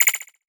Message Bulletin Echo 7.wav